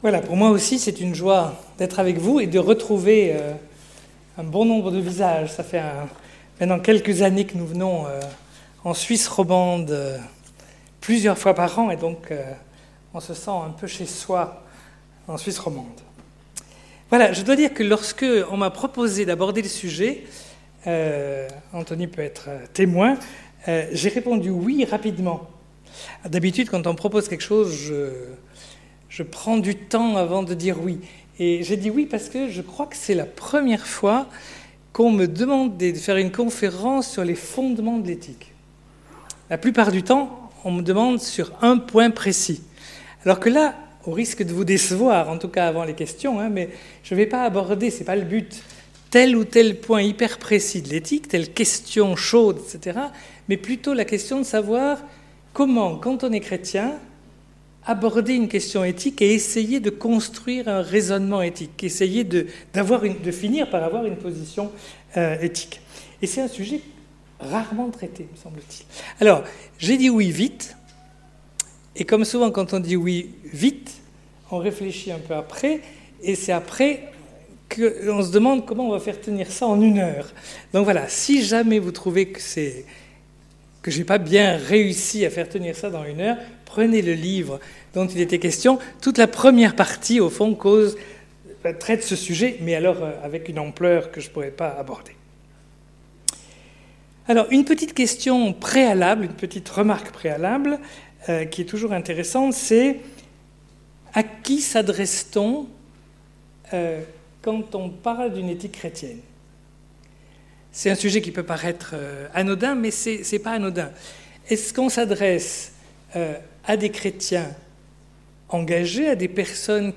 Conférence Frathéo du 4 octobre 2018